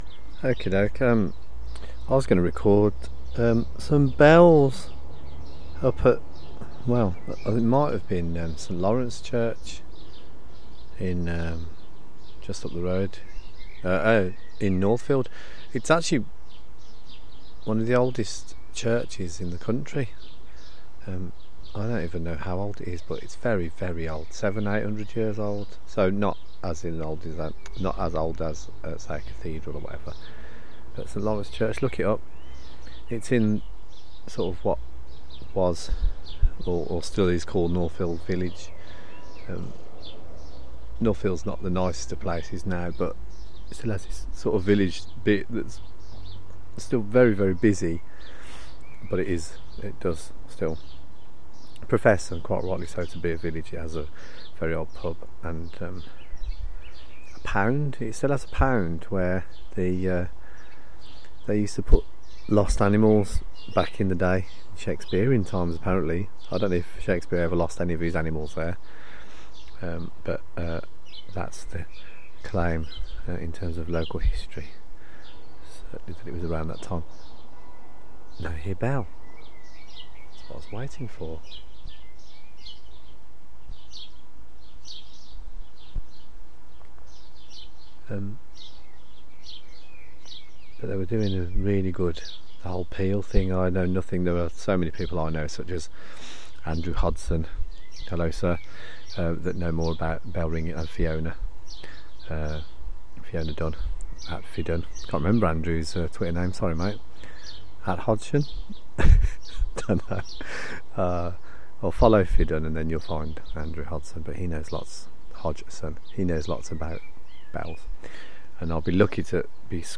In my Garden, the search for the almost illusive bell ringers in the distance.